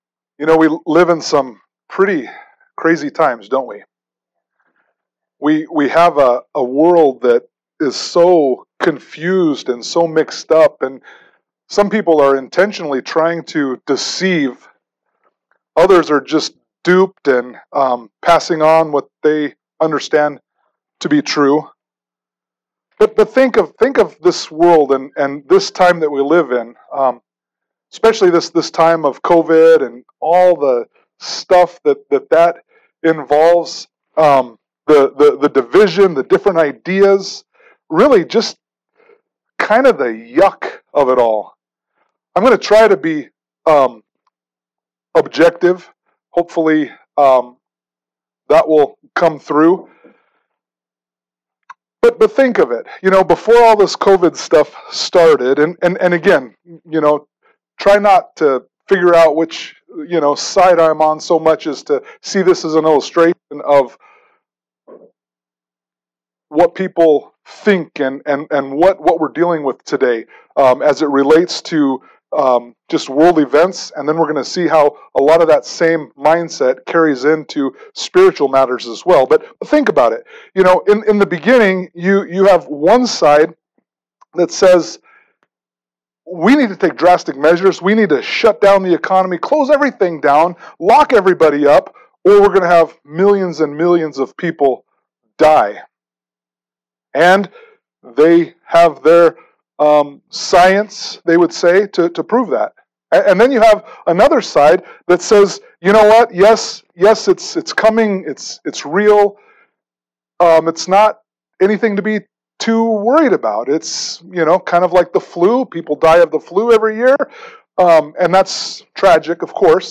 Passage: 1 John 1:1 Service Type: Sunday Morning Worship « Solomon